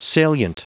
Prononciation du mot salient en anglais (fichier audio)
Prononciation du mot : salient